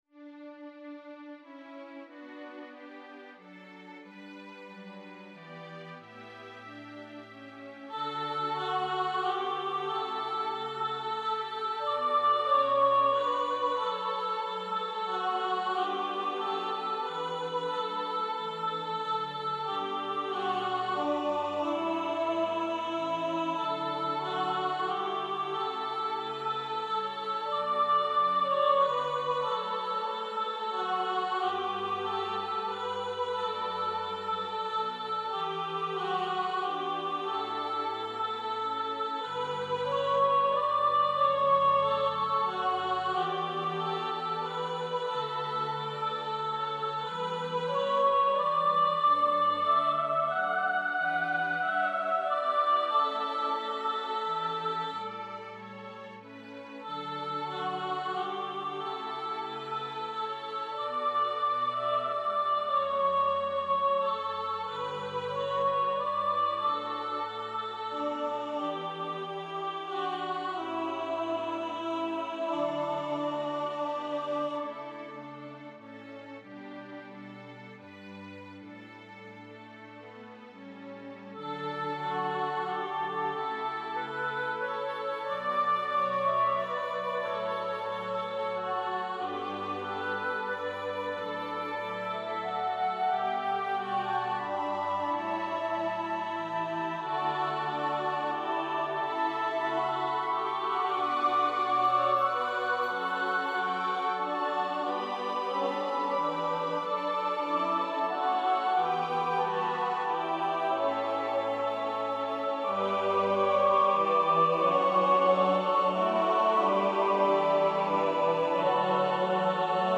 • Voicing: SATB, Soprano Solo
• Accompaniment: Flute, Keyboard, Strings